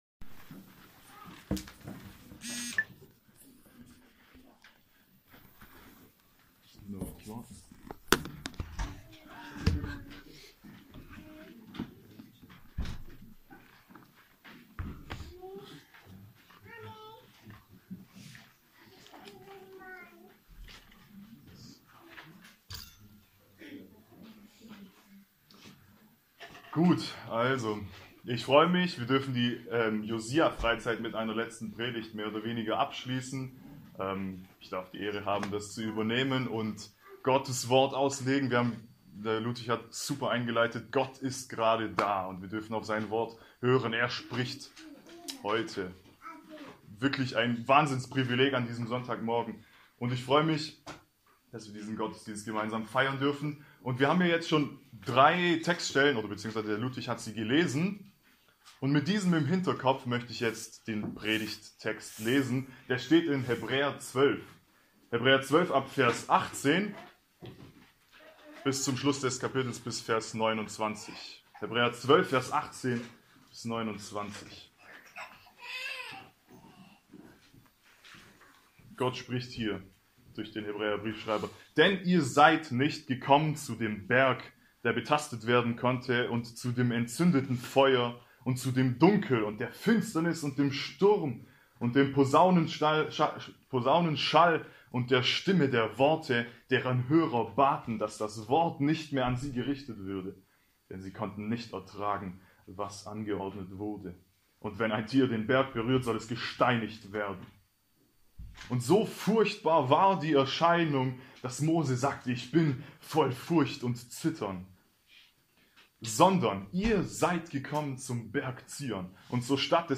Predigttext: Heb 12,18-29